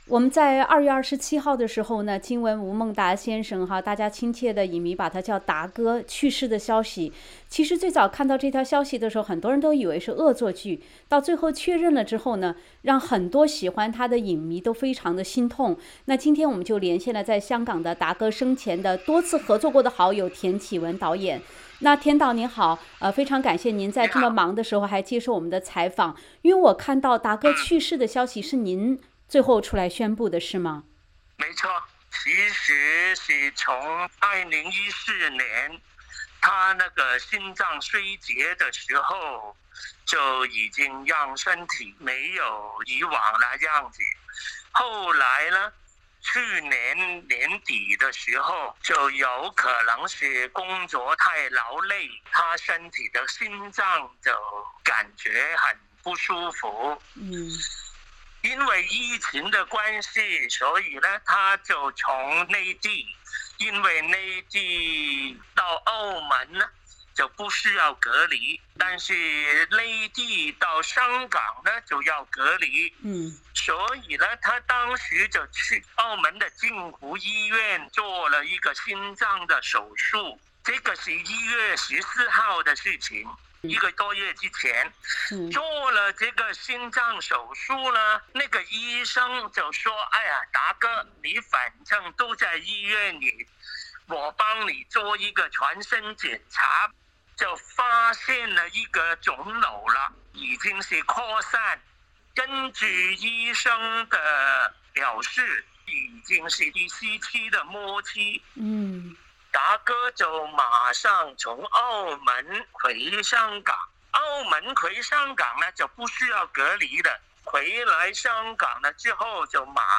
（点击封面图片，收听完整采访） 2月27日，被众多影迷亲切称为“达叔”的香港知名影星吴孟达先生离世的消息传出后，令很多喜欢他的影迷心痛不已，SBS普通话节目以最快的速度连线了达叔生前多次合作的好友田启文导演。